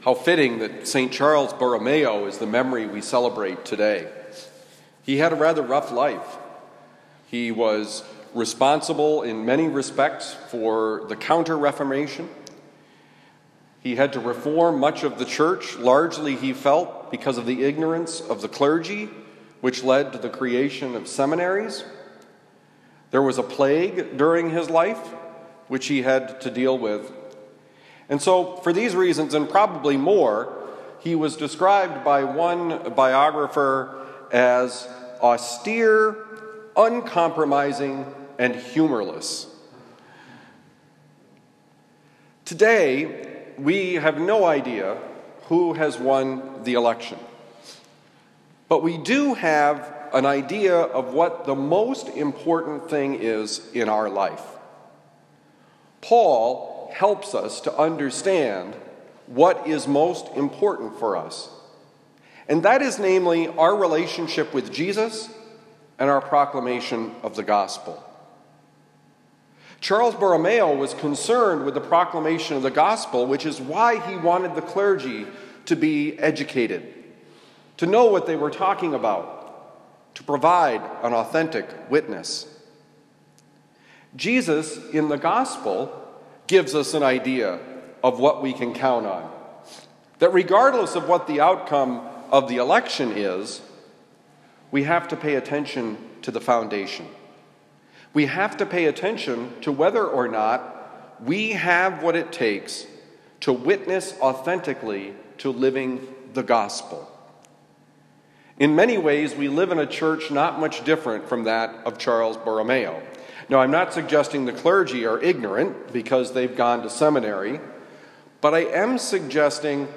Homily for November 4, 2020
Given at Saint Dominic Priory, Saint Louis, Missouri